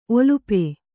♪ olupe